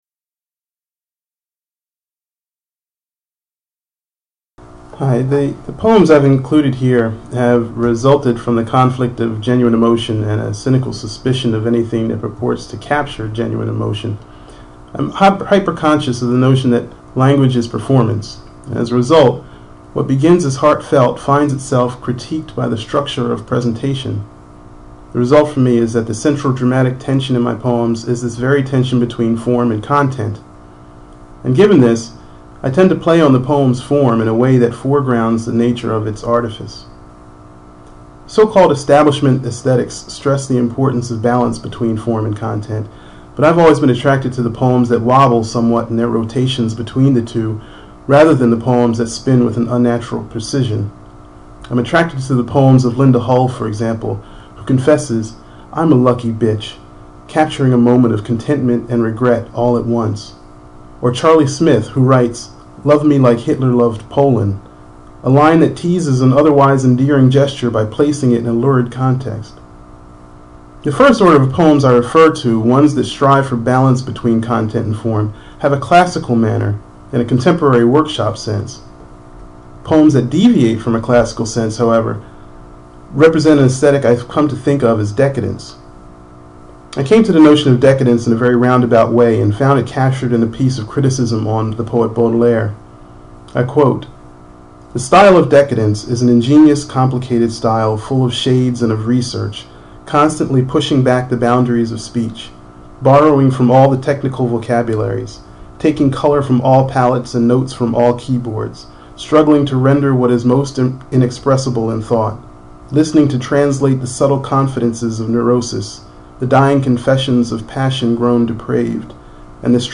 Gregory Pardlo Q&A discusses the dramatic tension of form and content
Gregory Pardlo, a 2005 New York Foundation for the Arts Fellow in poetry, talks about the dramatic tension between form and content in his poems.